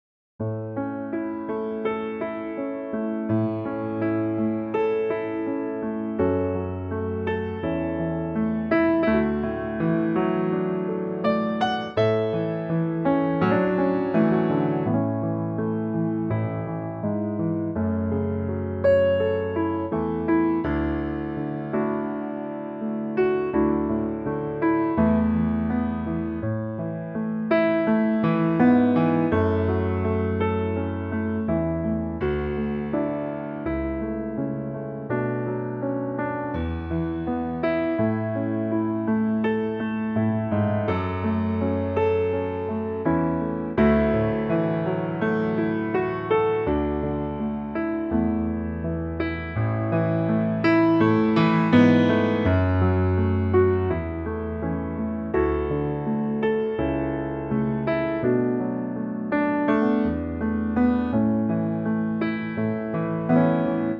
Unique Backing Tracks
key - A - vocal range - A to C#
Lovely piano only arrangement